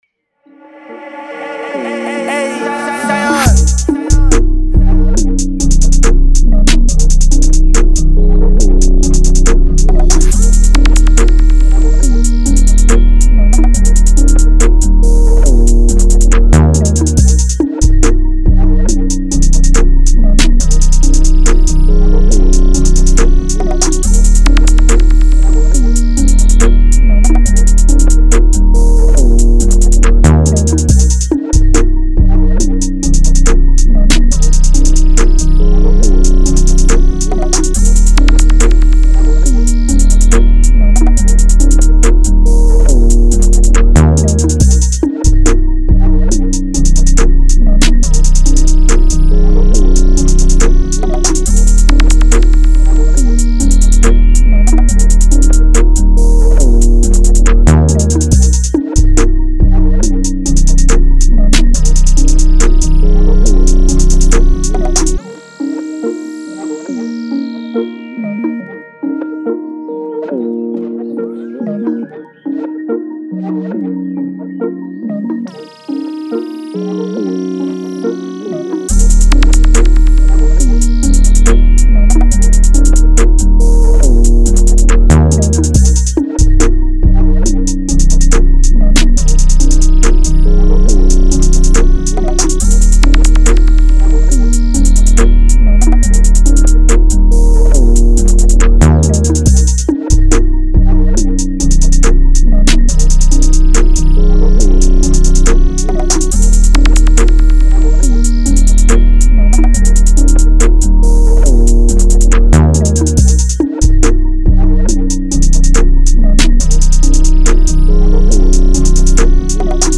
Surf
140 D# Minor